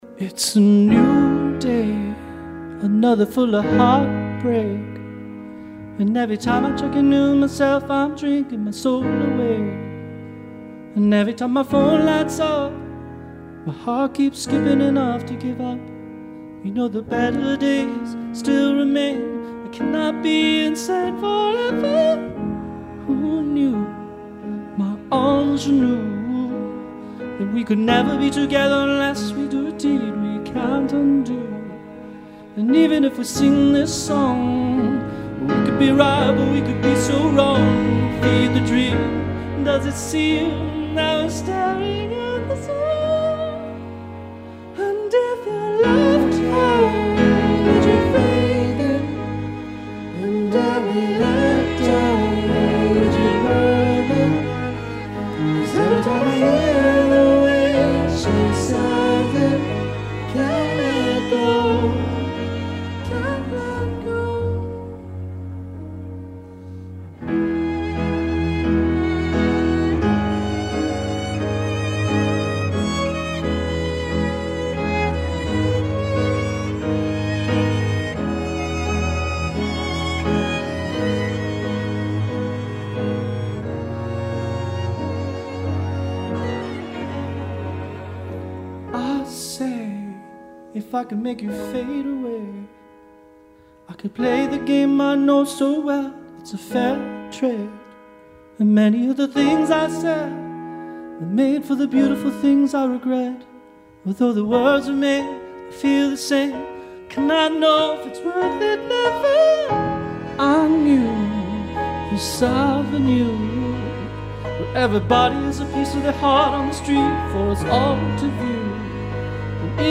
Culture Center Theater in Charleston, WV on June 25